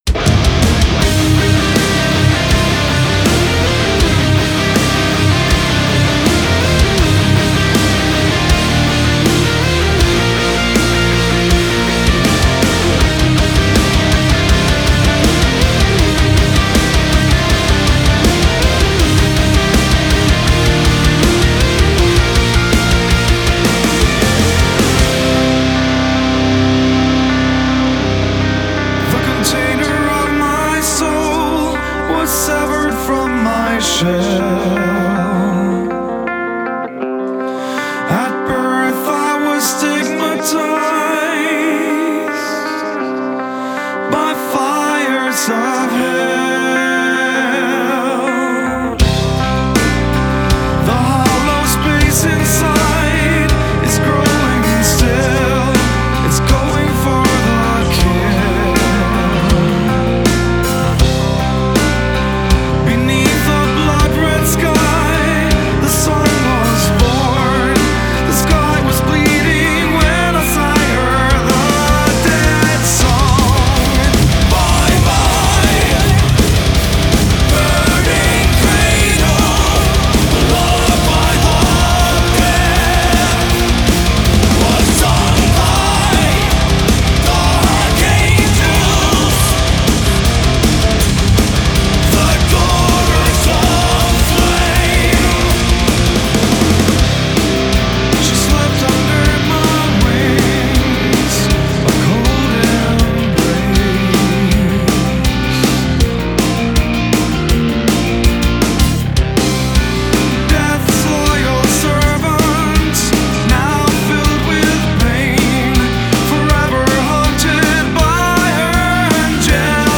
мелодичный дэт-метал или мелодичный дарк-метал.
— «меланхоличный скандинавский метал»).
вокал, гитара, ударные, клавишные
бас-гитара